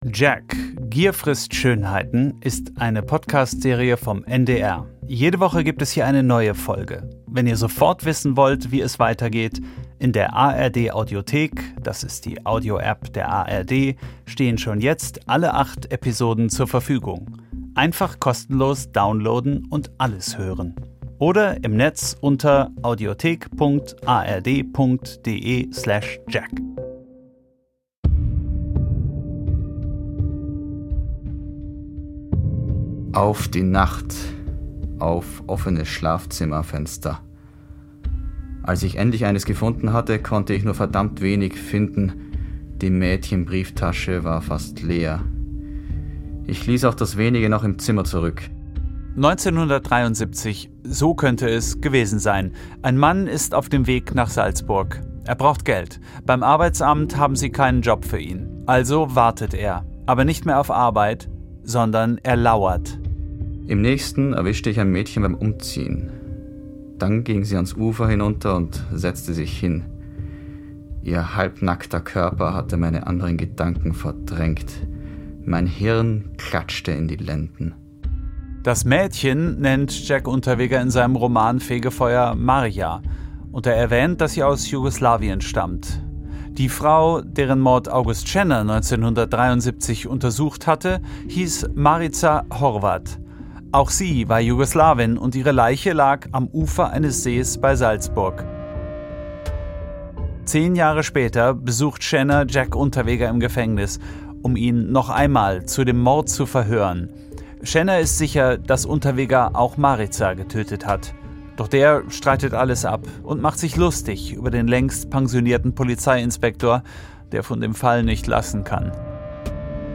In seiner Heimat Kärnten berichten Zeitzeug*innen, wie sie ihn als Kind erlebt haben.